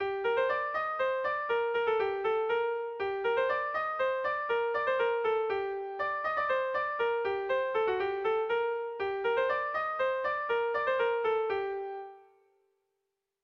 Erlijiozkoa
Zortziko txikia (hg) / Lau puntuko txikia (ip)
A1-A2-B-A2